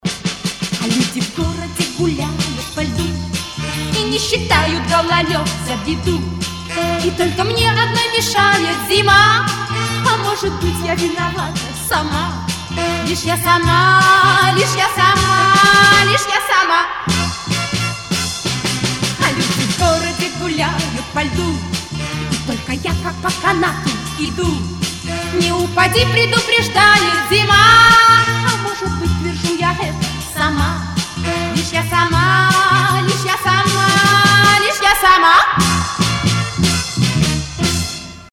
• Качество: 192, Stereo
веселые